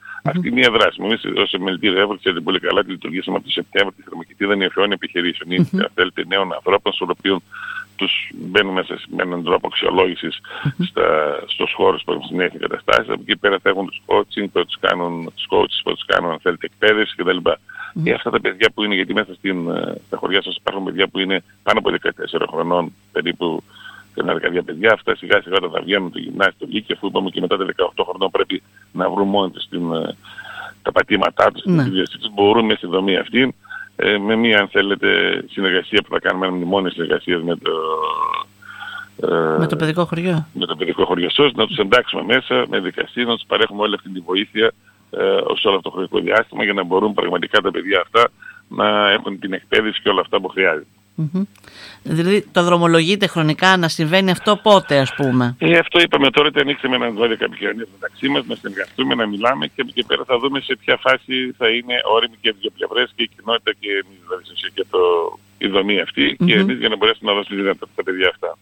Μιλώντας στην ΕΡΤ Ορεστιάδας